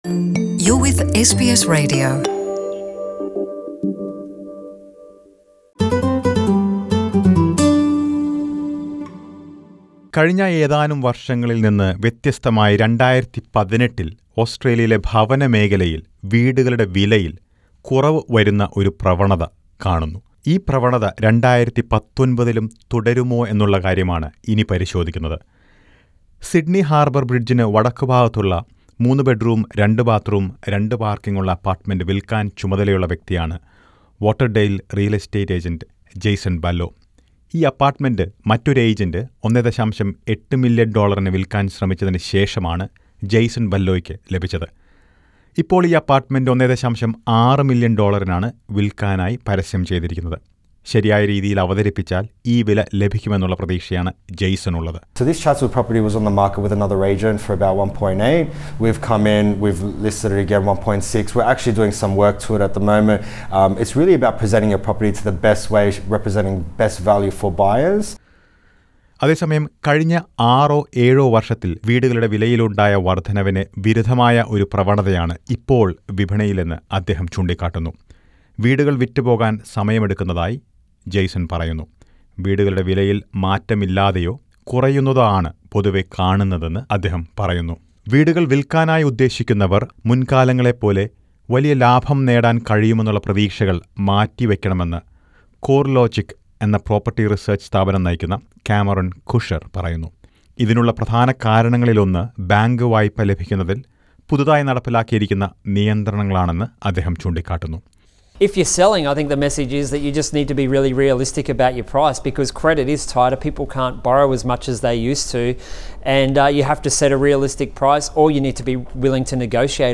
Is this trend going to continue in 2019? Listen to a report.